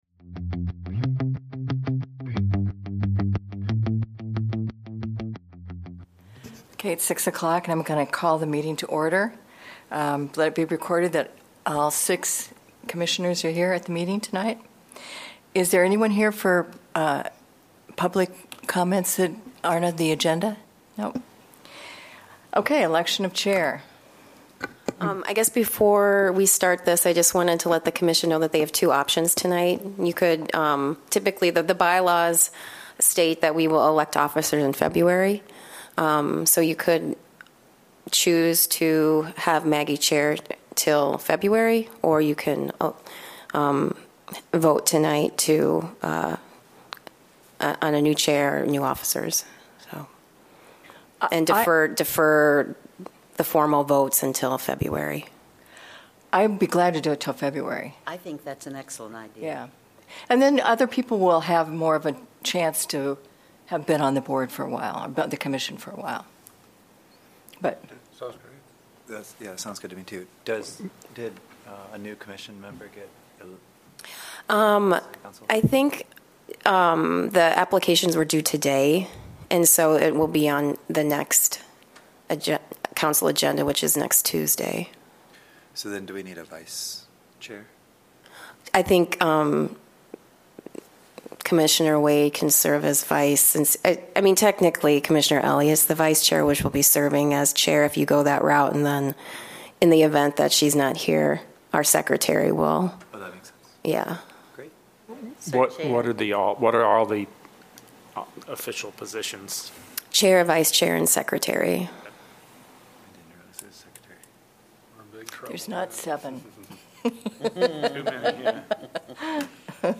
Regular semi-monthly meeting of the Planning and Zoning Commission.